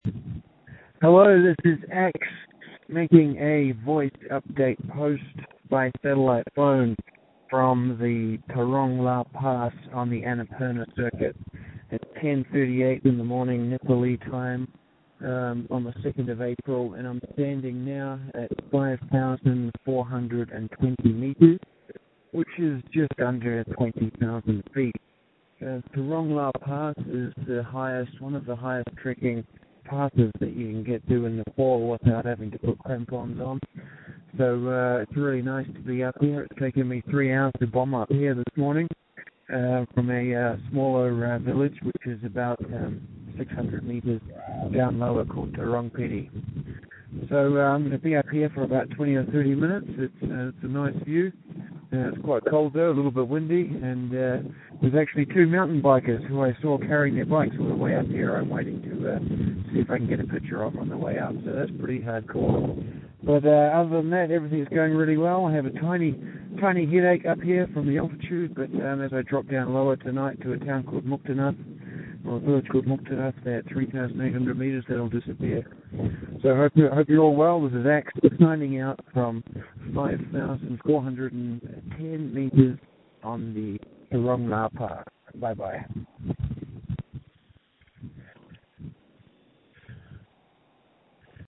I make a satelite phone update on my blog (listen to the voice post here).